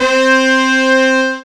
BRASSY.wav